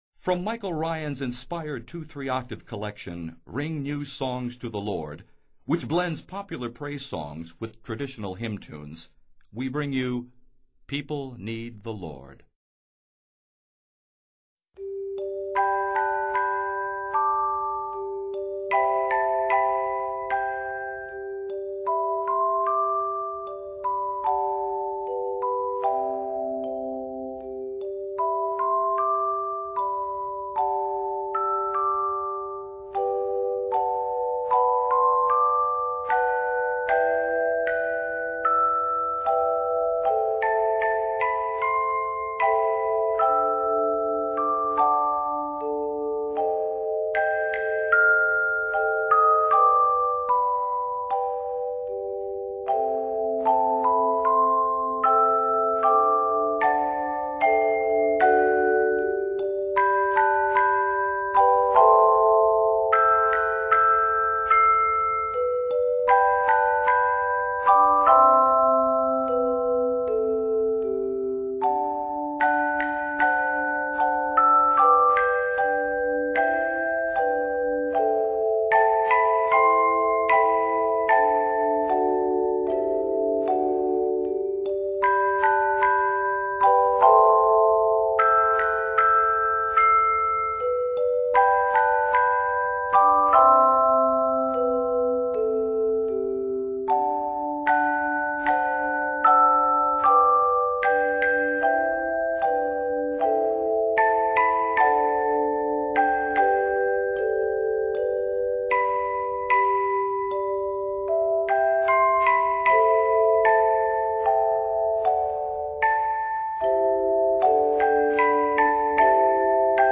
Scored for handbells or handchimes